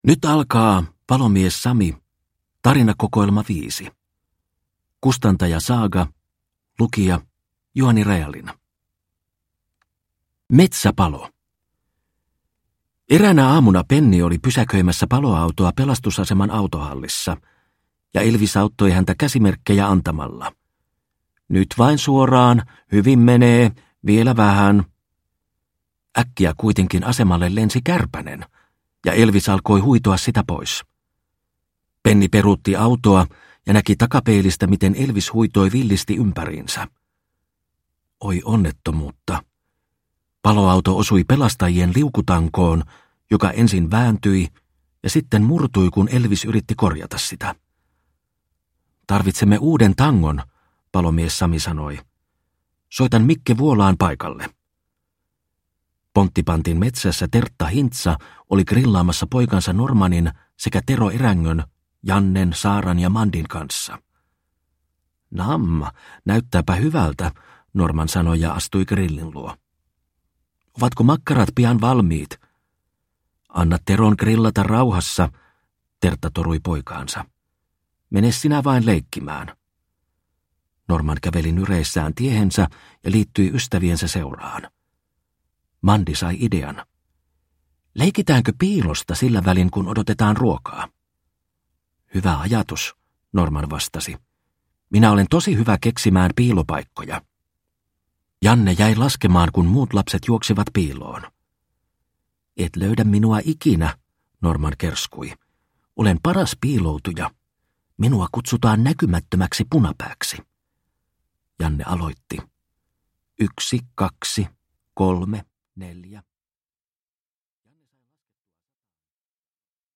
Palomies Sami - Tarinakokoelma 5 (ljudbok) av Mattel